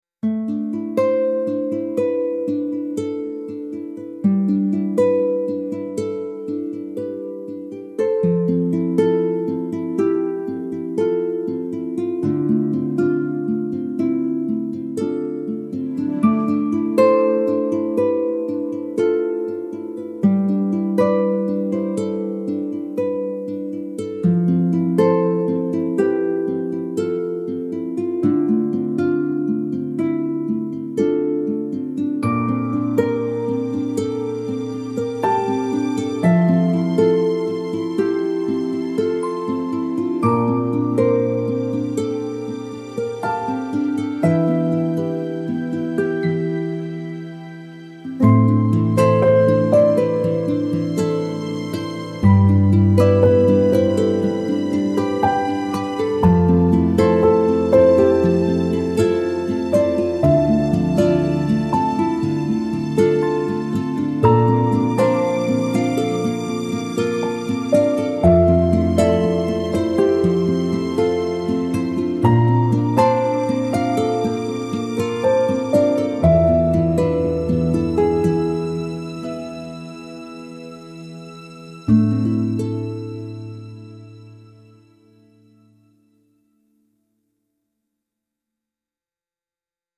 gentle love ballad instrumental with acoustic guitar and delicate harp